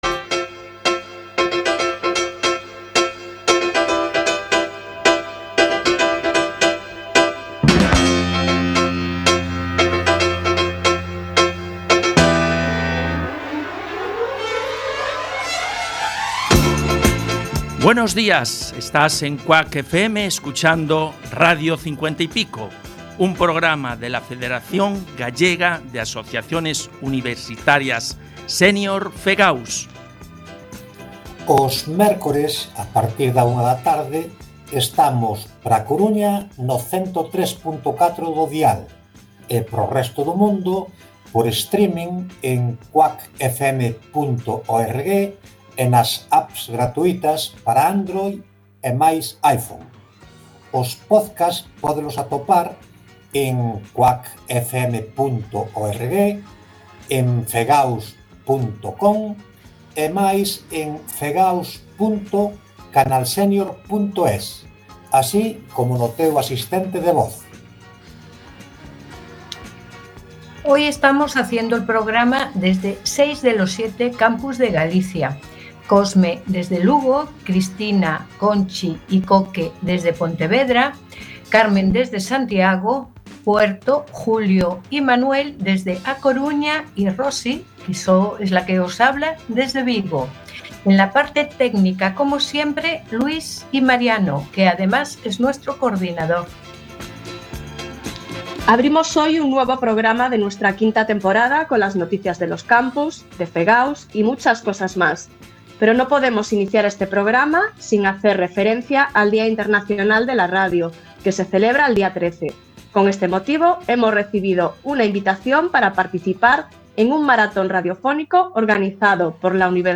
Radio 50 y Pico es un proyecto de comunicación de la Federación Gallega de Asociaciones Universitarias Senior (FEGAUS). Se realiza desde cinco de los siete campus universitarios de Galicia y aborda todo tipo de contenidos de interés, informativos, culturales, de actualidad y de entretenimiento.